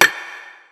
Perc 5 [ metal ].wav